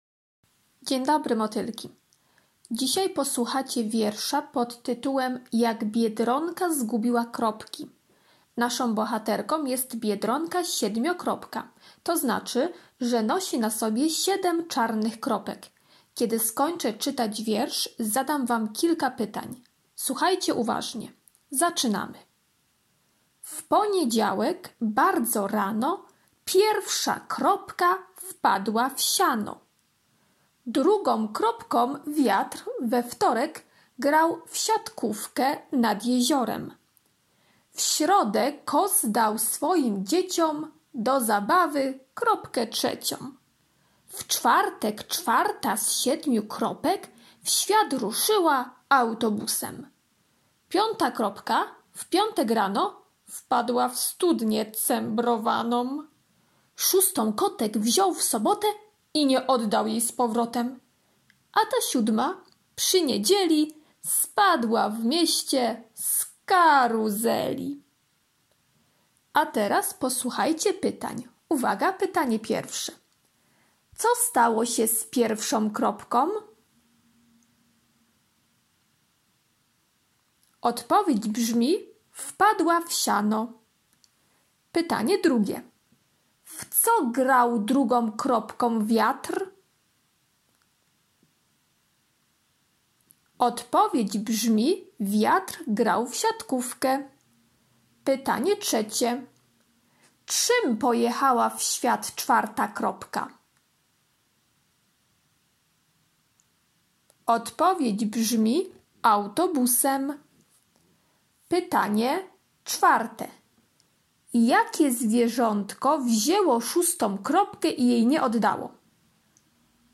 środa - wiersz o biedronce [4.10 MB] środa - ćw. dla chętnych "Ile biedronka ma kropek?"